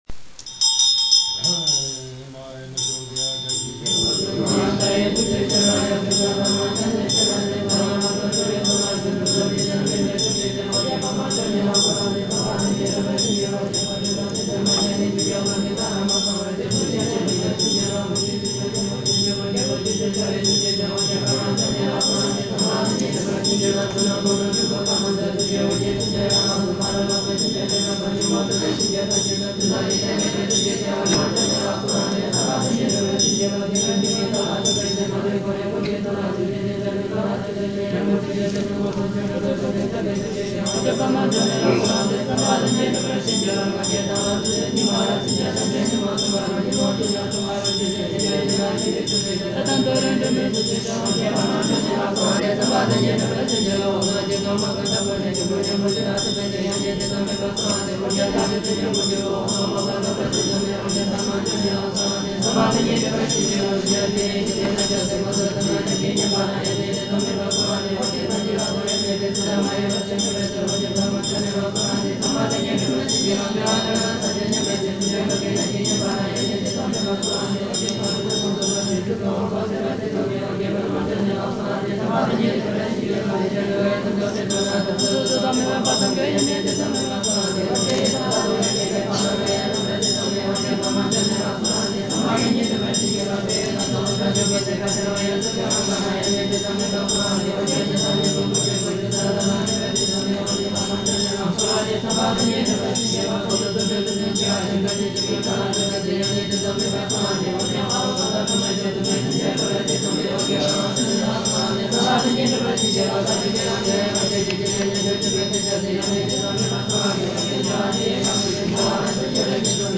瓦西扎格显密讲修院之众觉母念诵《吉祥经》